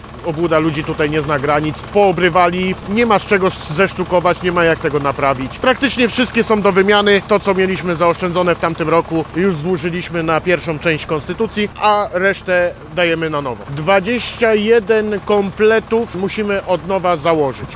Wiadomości